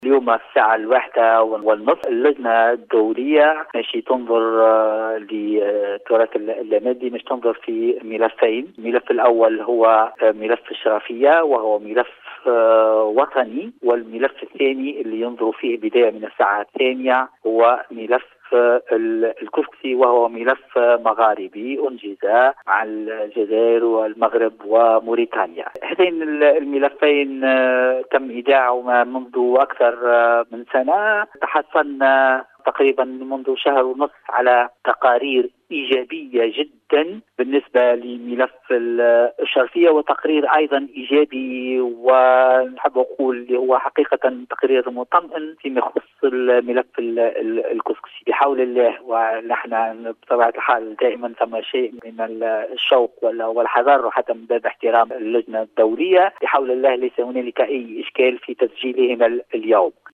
مدير عام معهد التراث لاذاعة المنستير : اليوم الحسم في ملفي الكسكسي والصيد بالشرفية بمنظمة اليونسكو | Sahel TV | قناة صوت الساحل التونسي
اعلن مدير عام معهد التراث فوزي محفوظ لقسم الاخبار اليوم اللاربعاء 16ديسمبر 2020 ان أشغال اجتماع اللجنة الدوليّة للتراث الثقافي اللامادي بمقر منظمة اليونسكو بباريس انطلقت منذ امس الثلاثاء .